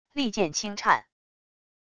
利剑轻颤wav音频